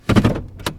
GearShifting2.WAV